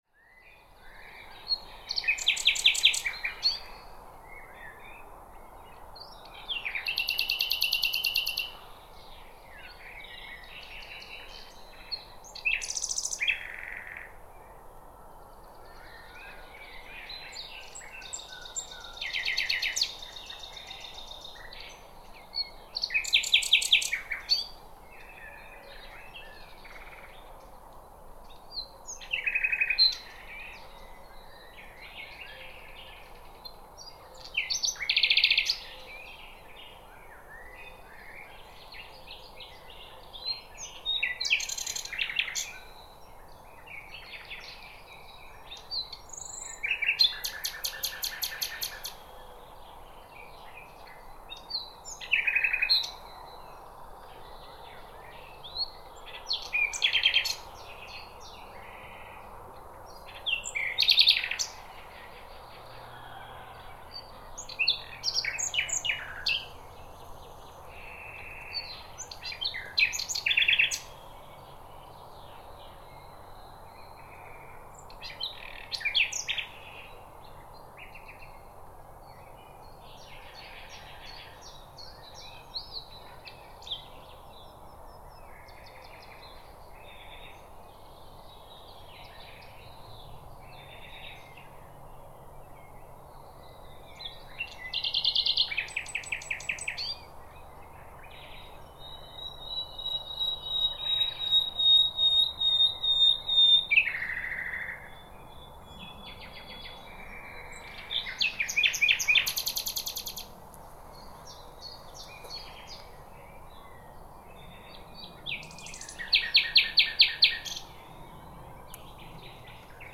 Bird Singing Sound Effect
A cheerful birds’ chirping sound brings a natural and lively atmosphere to your projects.
Bird-singing-sound-effect.mp3